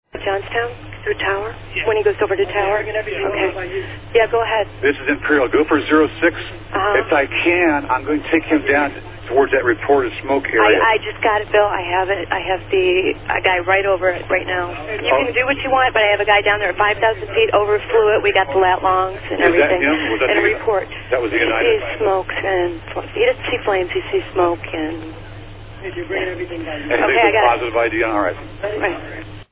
This next conversation, an internal one at Cleveland Center, confirms that Cleveland had used a different plane and that the “lat long” of the crash site had been verified.